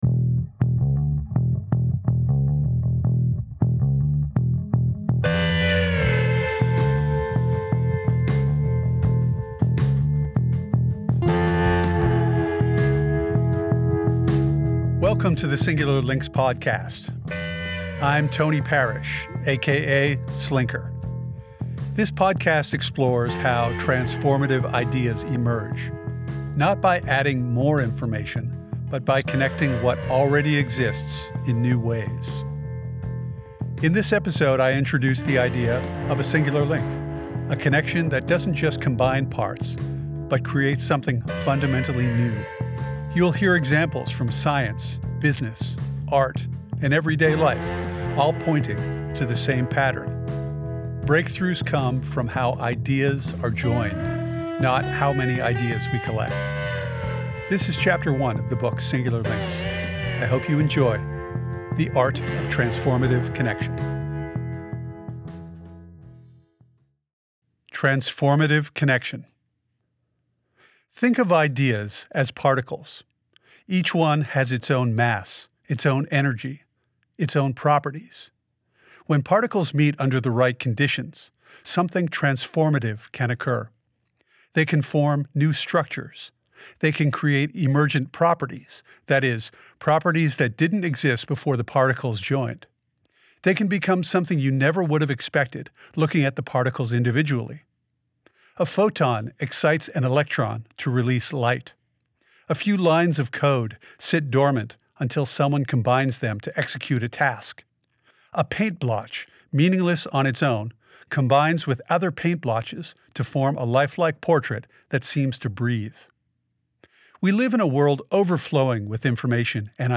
This episode features Chapter One of the audio book Singular Links.